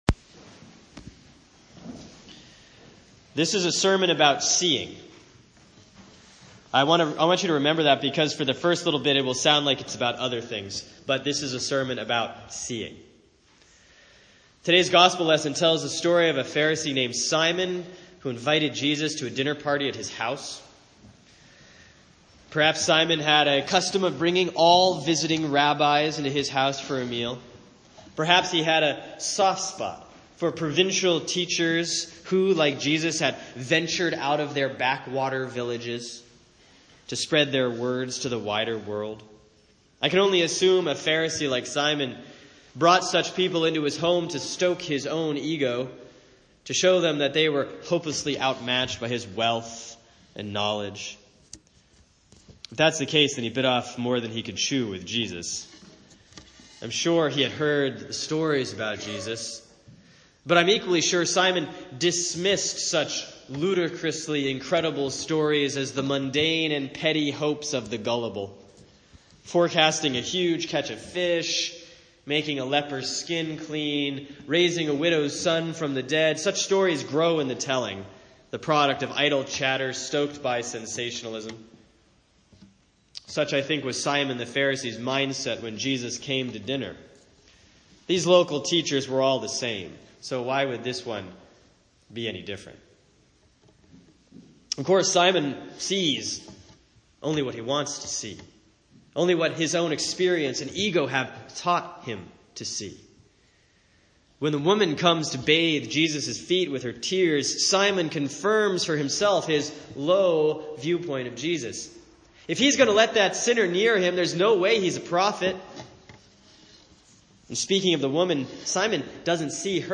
Sermon for Sunday, June 12, 2016 || Proper 6C || Luke 7:36–8:3